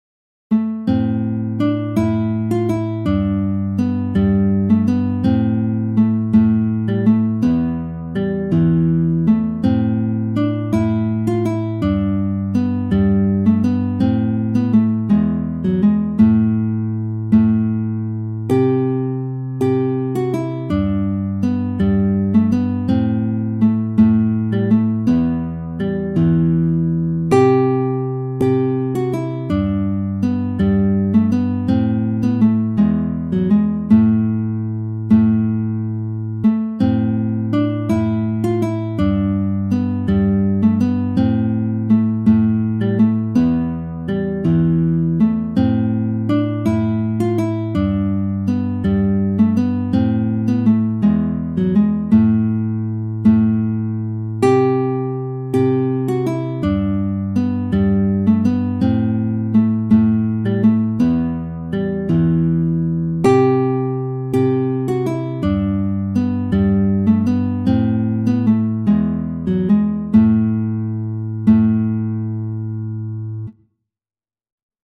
Traditional English Folk Song
for Guitar Solo